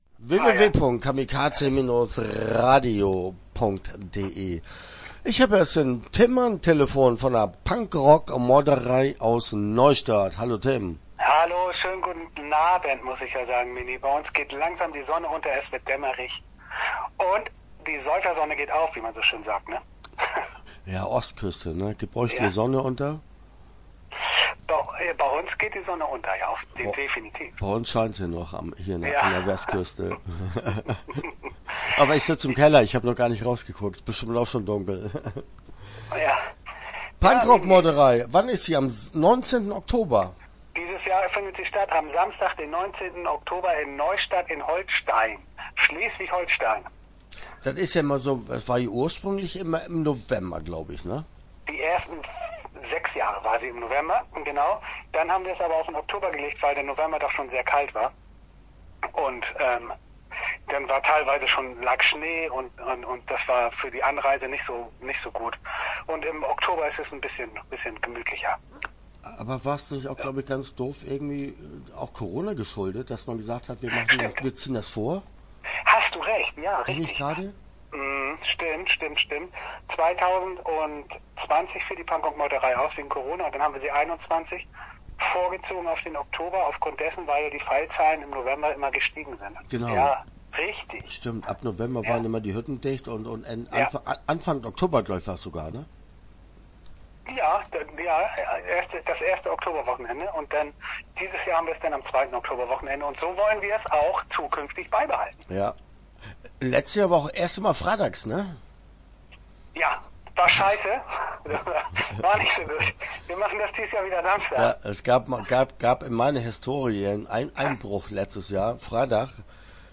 Start » Interviews » PunkRock-Meuterei - Neustadt/Ostsee